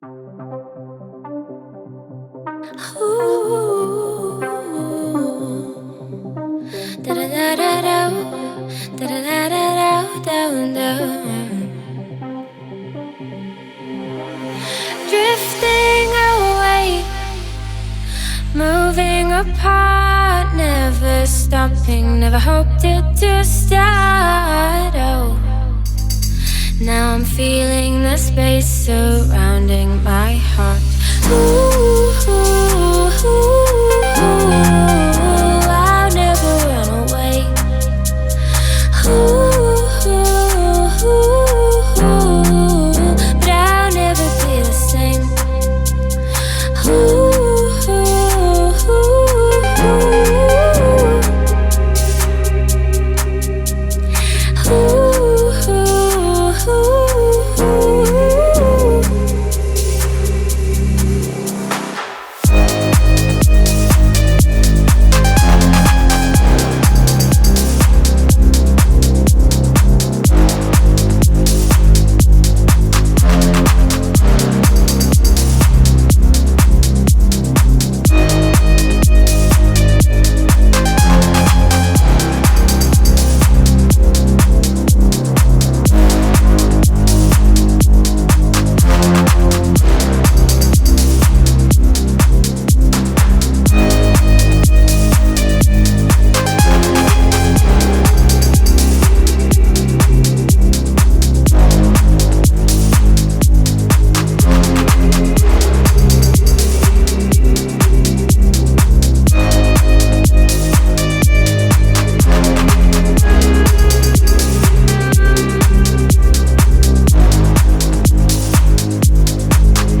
это завораживающий трек в жанре прогрессив хаус